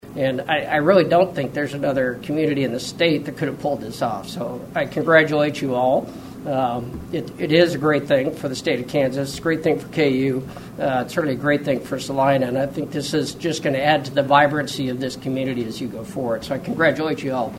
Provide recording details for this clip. Dignitaries were among a large crowd that gathered to celebrate the opening of the Salina Health Education Center, the new home of the University of Kansas School of Medicine-Salina and the KU School of Nursing-Salina. KU-Open-House-Chancellor.mp3